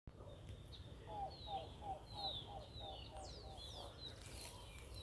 Papa-lagarta-acanelado (Coccyzus melacoryphus)
Nome em Inglês: Dark-billed Cuckoo
Localidade ou área protegida: Entre Santa Rosa y Toay
Condição: Selvagem
Certeza: Gravado Vocal
Cuclillo-Chico.mp3